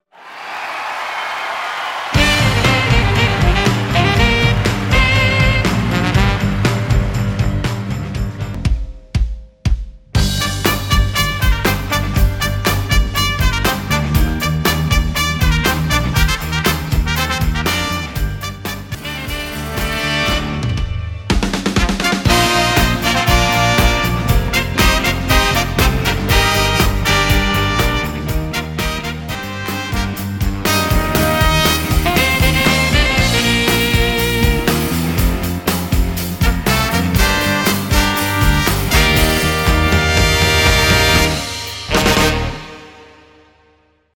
分類 並足120 時間 2分47秒
編成内容 大太鼓、中太鼓、小太鼓、シンバル、トリオ 作成No 301